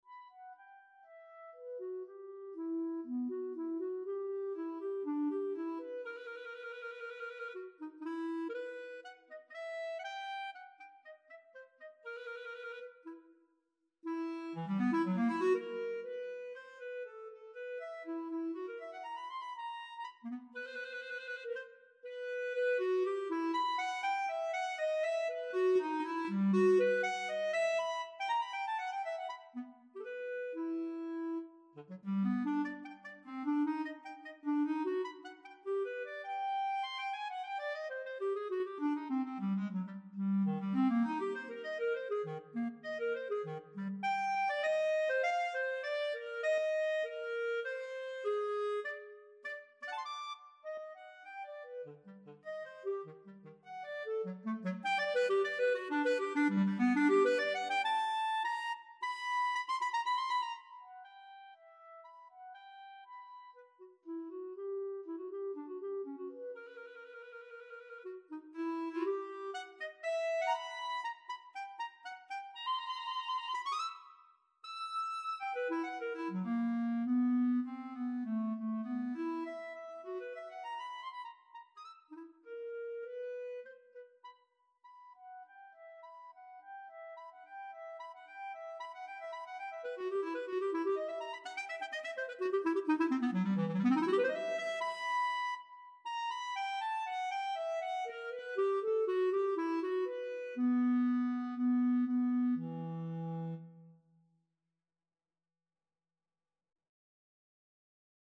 Piece for Clarinet in B-flat
piece-for-clarinet-in-b-flat-audio.mp3